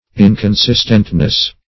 Inconsistentness \In`con*sist"ent*ness\, n.